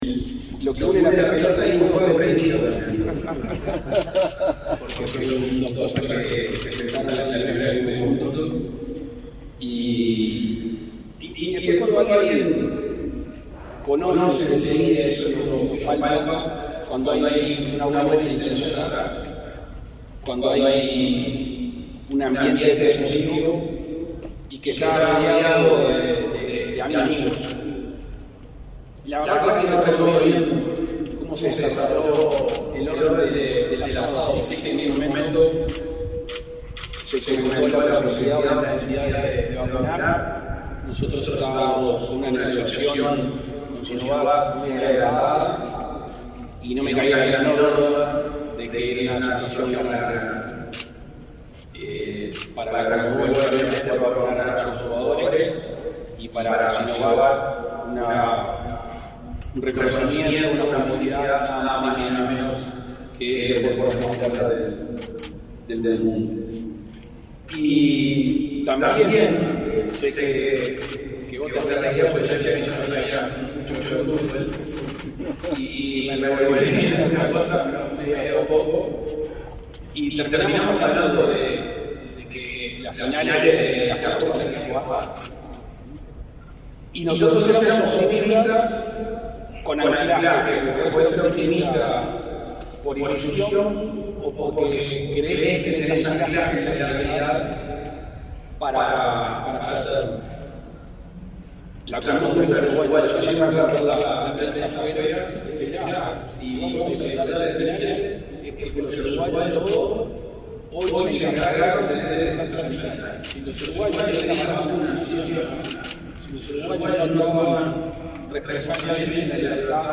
Palabras del presidente de la República, Luis Lacalle Pou
El presidente de la República, Luis Lacalle Pou, participó, este 18 de noviembre, en la inauguración del nuevo sistema de luces del estadio Centenario